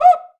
LCUICA HI.wav